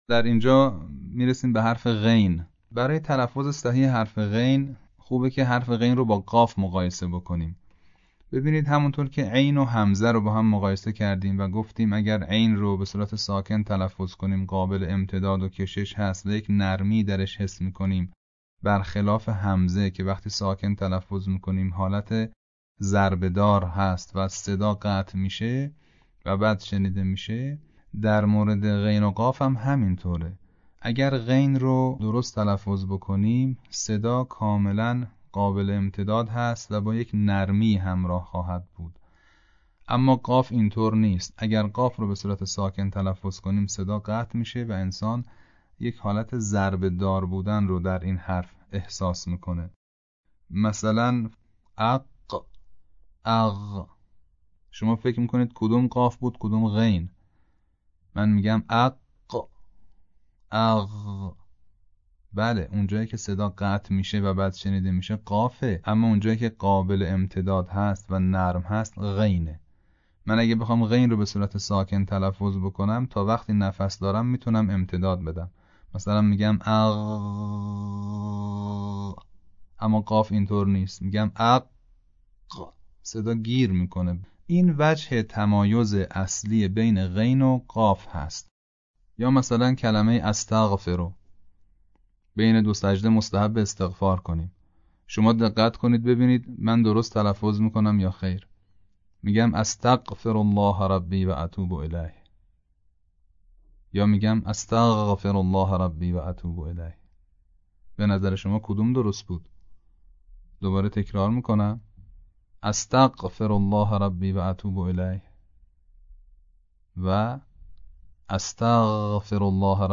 دسته : آموزش زیبا خوانی نماز